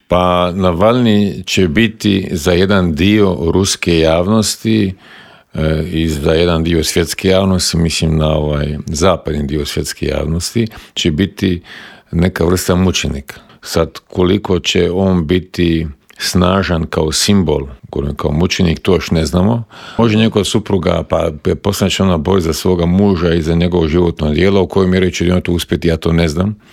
ZAGREB - Uoči druge godišnjice početka ruske agresije na Ukrajinu, u Intervjuu Media servisa razgovarali smo s bivšim ministrom vanjskih poslova Mirom Kovačem, koji nam je kratko proanalizirao trenutno stanje u Ukrajini, odgovorio na pitanje nazire li se kraj ratu, a osvrnuo se i na izbor novog glavnog tajnika NATO saveza i na nadolazeći sastanak Europskog vijeća.